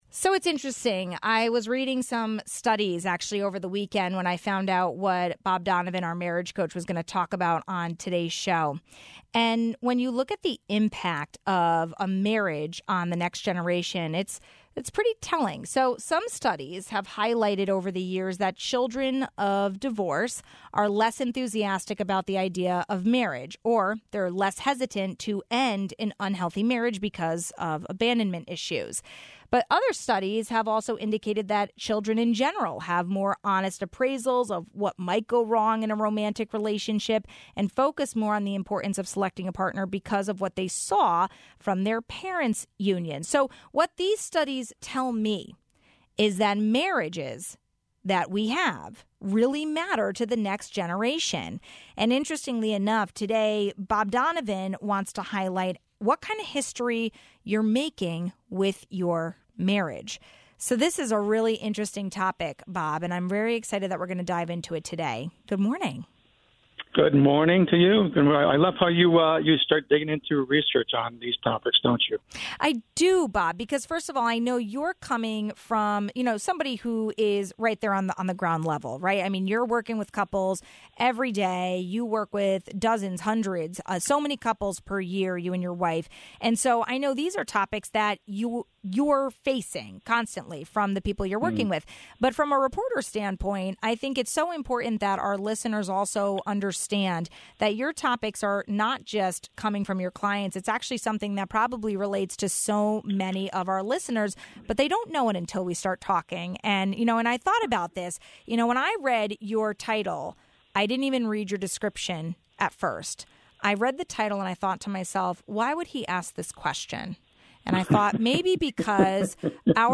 What these studies tell me is that the marriages we have matter to the next generation. Marriage coach